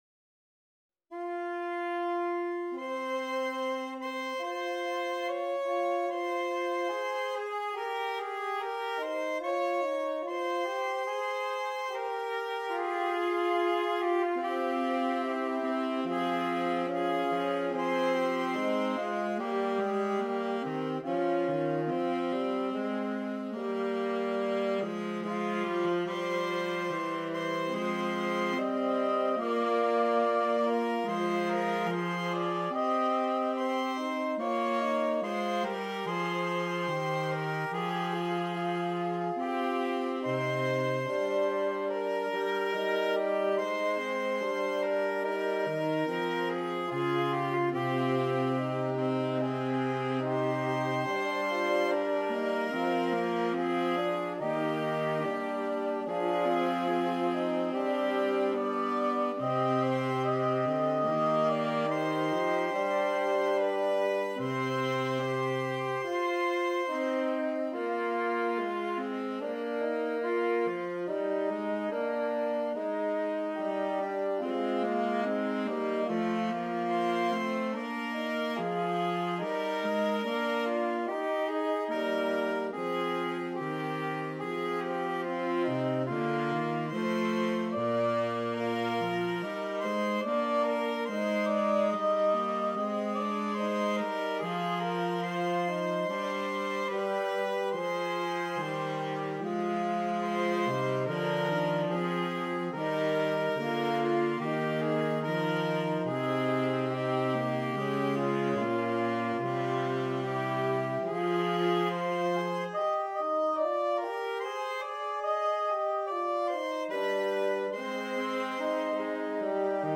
Gattung: Für 4 Saxophone
eindringlichen langsamen Werk